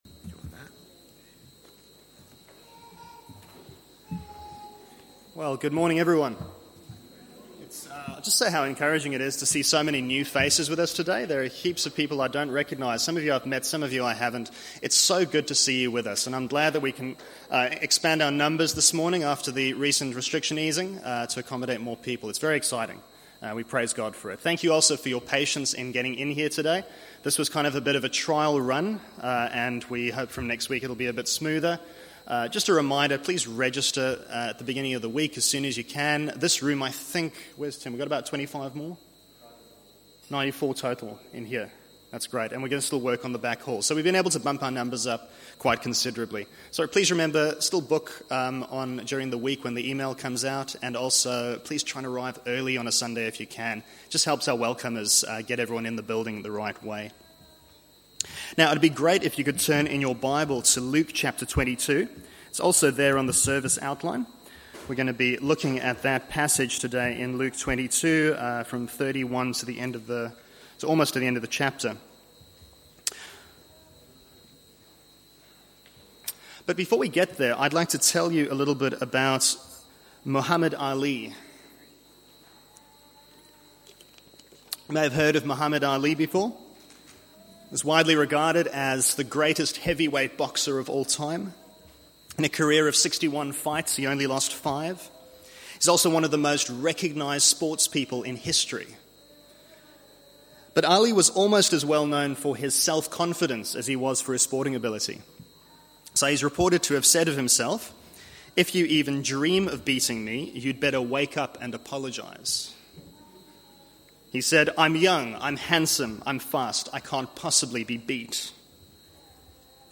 Search for Sermons